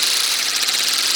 Coin Hardcover Spin Loop.wav